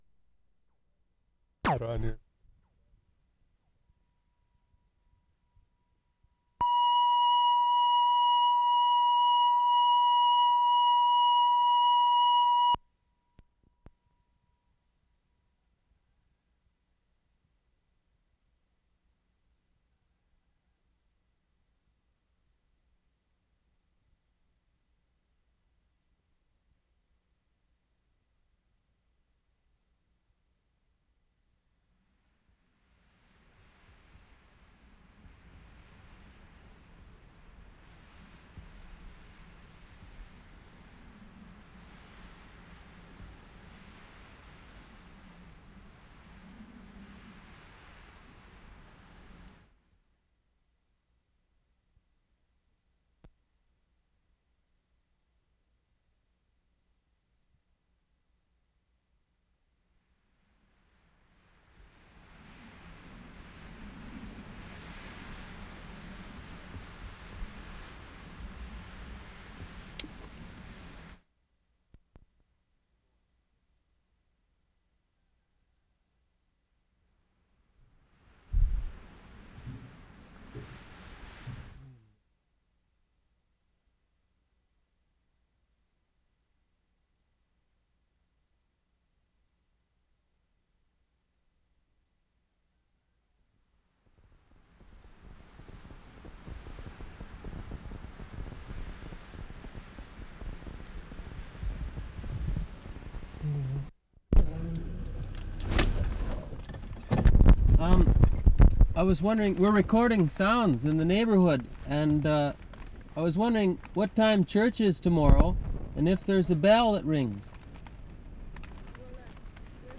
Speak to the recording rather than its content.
TWILLINGATE, NEWFOUNDLAND Oct. 6, 1973 ASKING INFORMATION ON CHURCHES AND BELLS, from car 1'20"